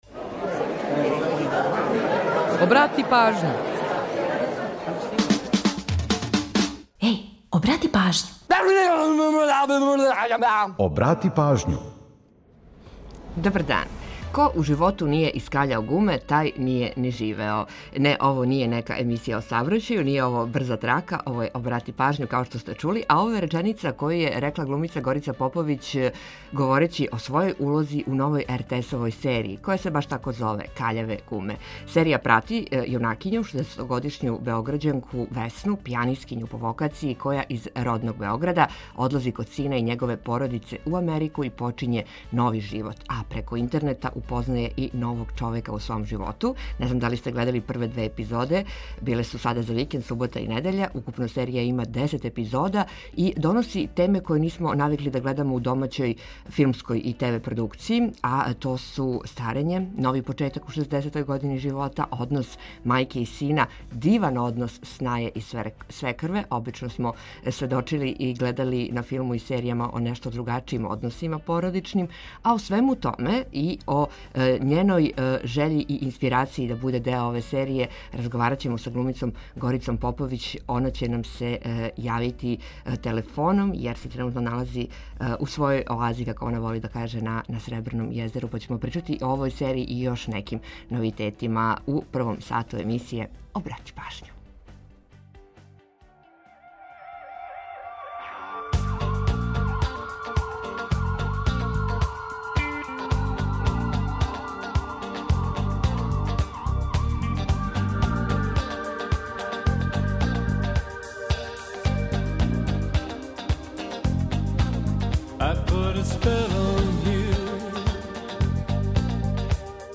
О темама, које нисмо навикли да гледамо у домаћој ТВ и филмској продукцији, а које ова серија покреће као што су: старење, нови почетак у 60. години живота, однос мајке и сина, диван однос снаје и свекрве, разговарамо са глумицом Горицом Поповић . У наставку емисије подсетићемо вас на важне догађаје у поп рок историји који су догодили на данашњи дан. Ту је и пола сата резервисаних за домаћицу, музику из Србије и региона, прича о једној песми и низ актуелних занимљивости и важних информација.